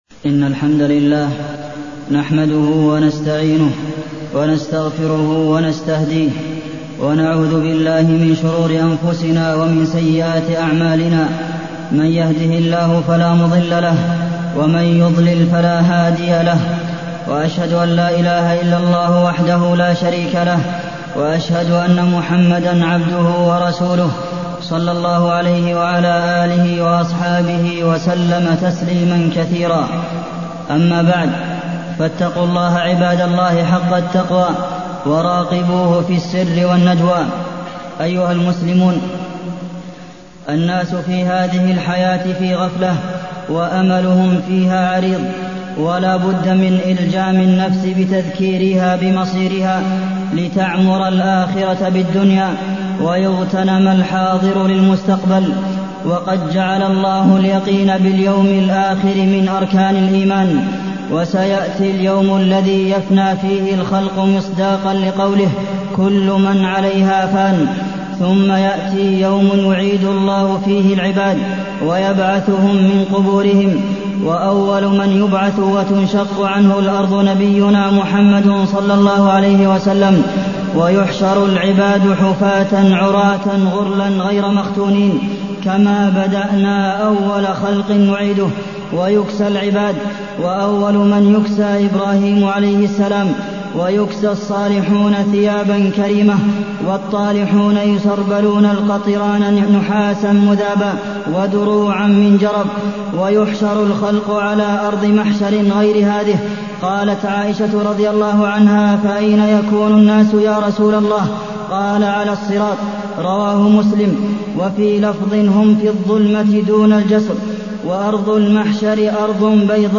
تاريخ النشر ٣٠ رجب ١٤٢١ هـ المكان: المسجد النبوي الشيخ: فضيلة الشيخ د. عبدالمحسن بن محمد القاسم فضيلة الشيخ د. عبدالمحسن بن محمد القاسم اليوم الآخر